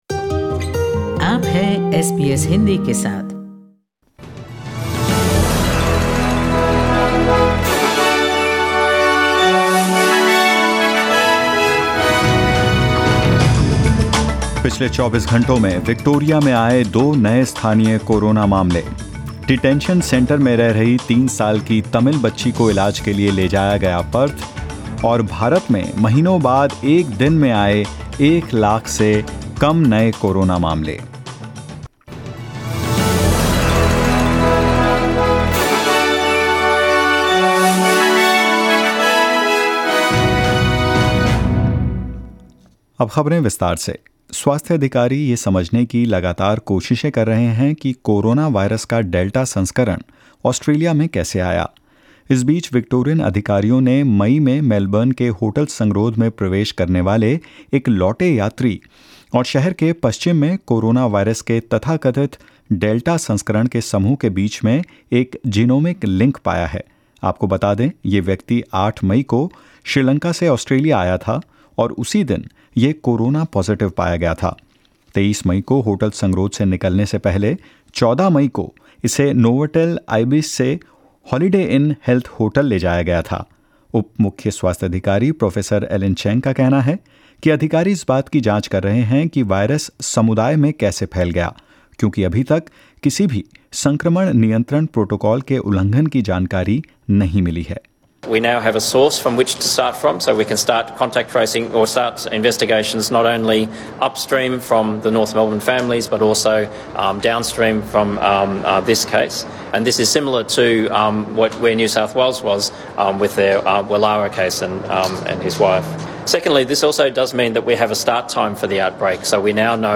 In this latest SBS Hindi News bulletin of Australia and India: Victorian health authorities are confident of easing restrictions later this week despite two new local Covid-19 cases; India's daily coronavirus cases fell below 100,000 for the first time in 68 days and more.